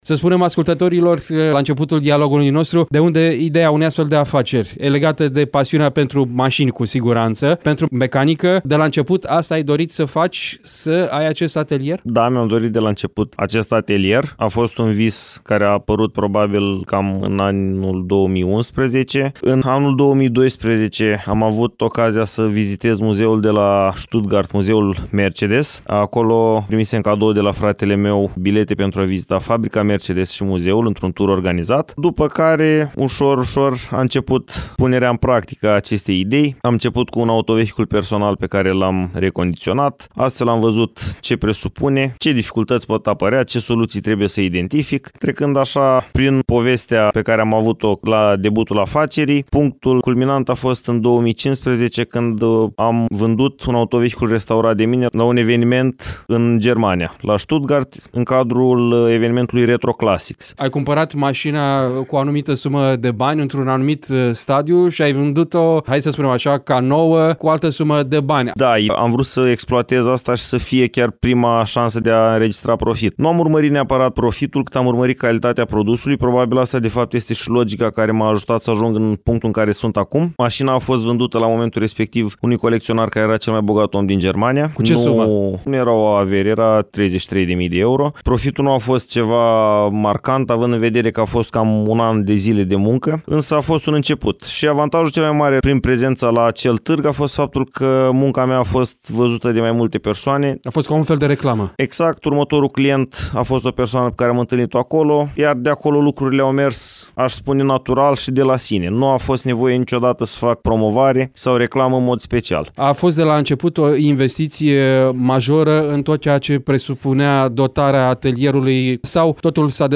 Varianta audio a interviului